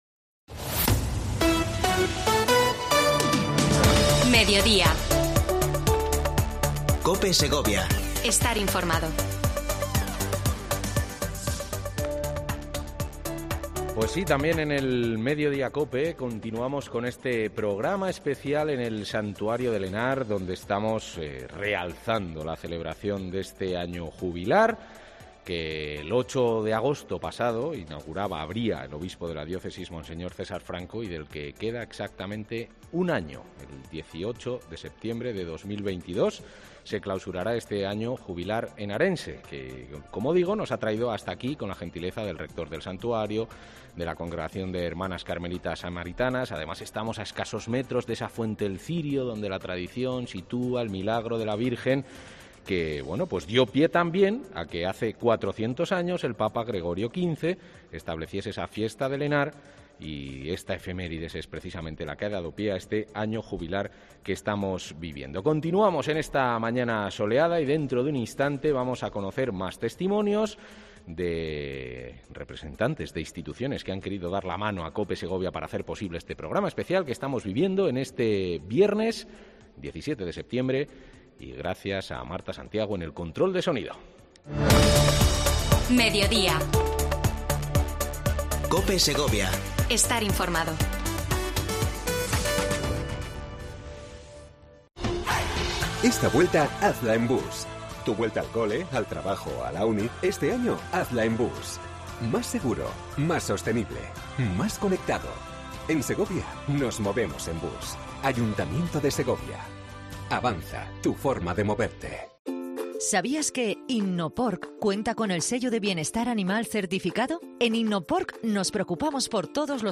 PROGRAMA ESPECIAL AÑO JUBILAR HENARENSE DESDE EL SANTUARIO VIRGEN DE EL HENAR